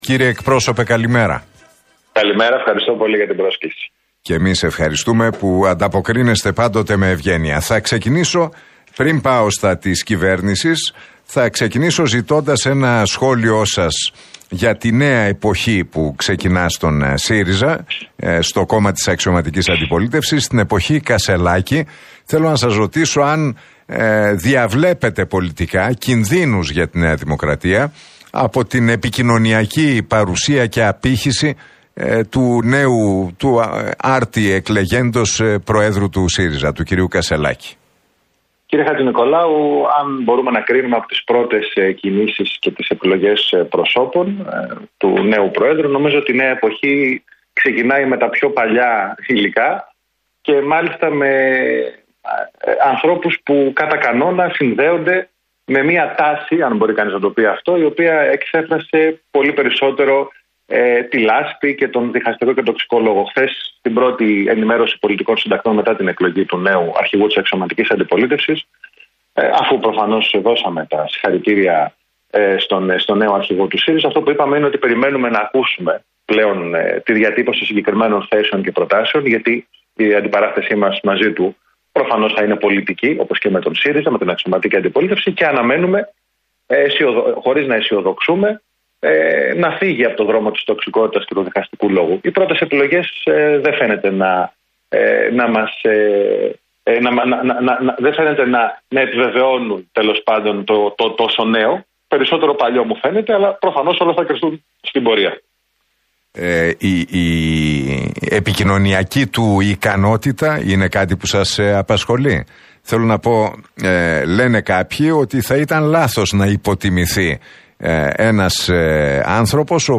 Μαρινάκης στον Realfm 97,8 για Κασσελάκη: Όλοι πρέπει να κρίνονται εκ του αποτελέσματος